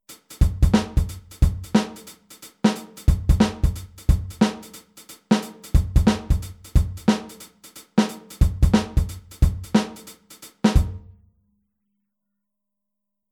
Der Handsatz beim Shufflen
Groove12-24tel.mp3